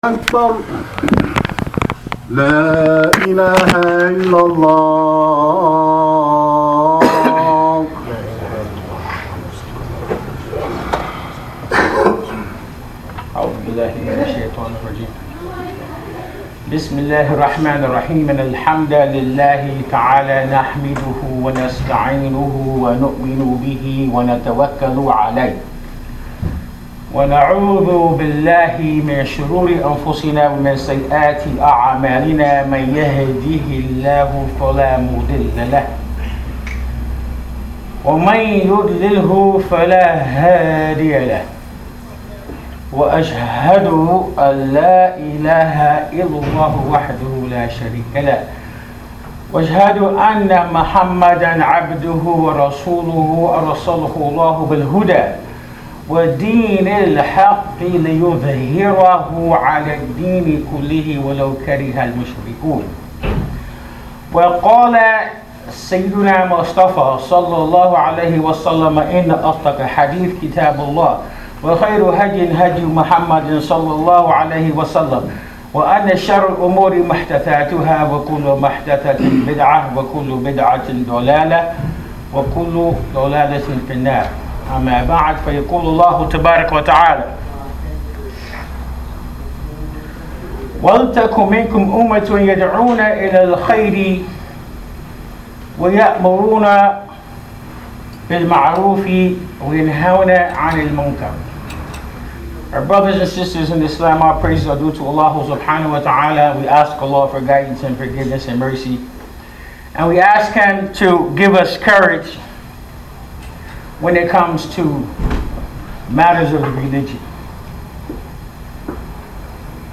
This is the topic of this khutbatul Jum’ah recorded at Masjid Ibrahim Islamic Center in Sacramento Ca. Click on the link below to take a listen.